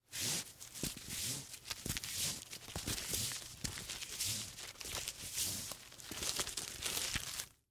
Звуки каната, троса
Шуршание разматываемой веревки